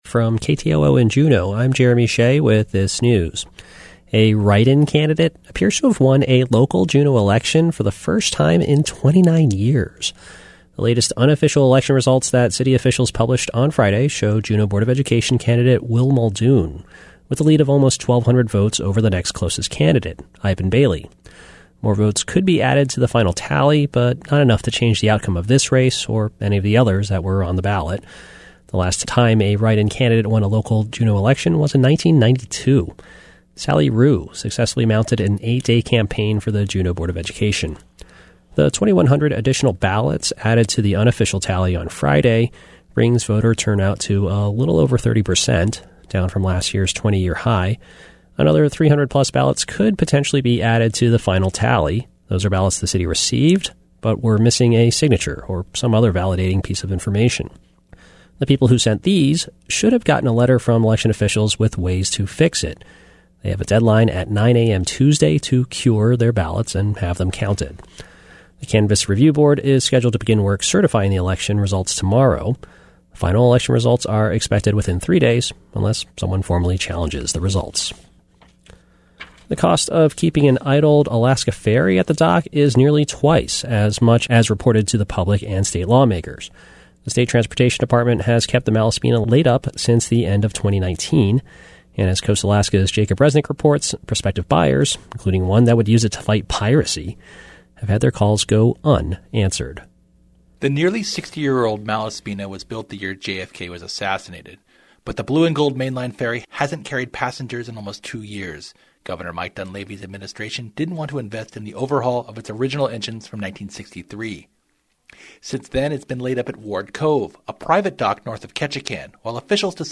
Newscast – Monday, Oct. 18, 2021